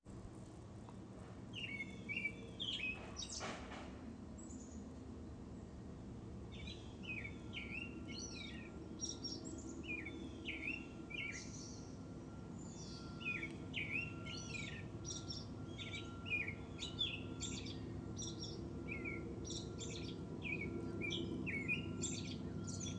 Here's (hopefully) 20 seconds of the birds just givin'er outside my window this morning.